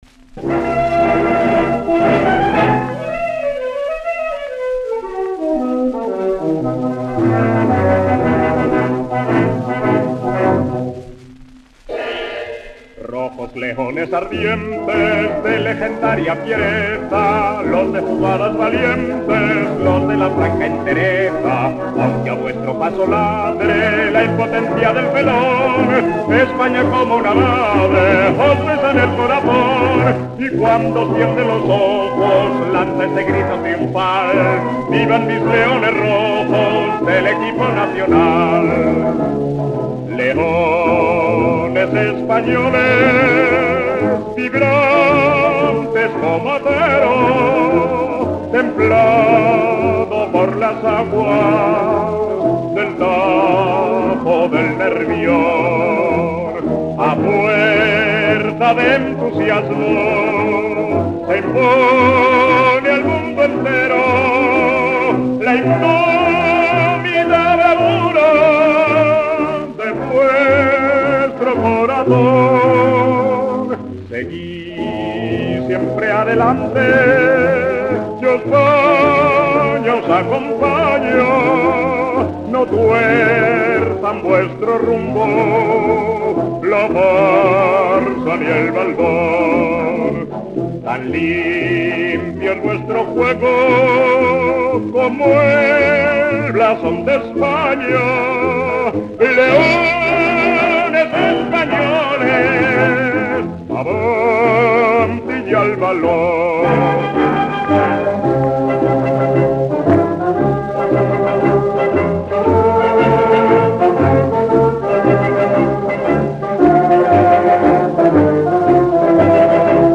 Himno de la selección nacional de fútbol. 1934.